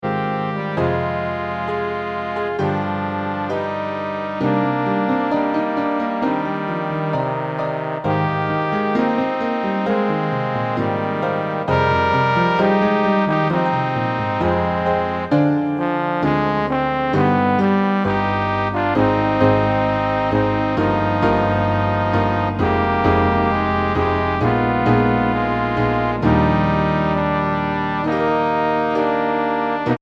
tenor trombone, bass trombone, and piano